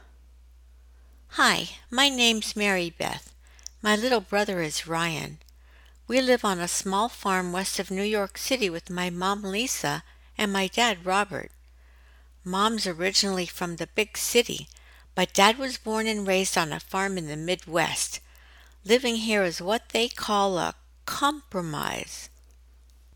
This sample should sound like a little girl is narrating.
ACX’s audio lab reports this sample: Issue Type: RMS (dB RMS) Value: -27.4 Resolution: RMS is too low.